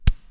punch_sound.wav